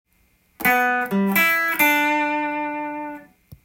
エレキギターで弾ける【Gミクソリディアンフレーズ集】オリジナルtab譜つくってみました
④のフレーズは、殆どG7のコードトーンですが
２弦の５フレットのミの音が入ることで滑らかさを出しています。